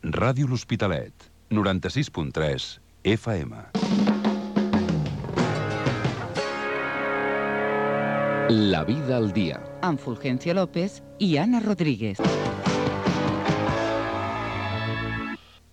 Indicatiu de l'emissora i separador del programa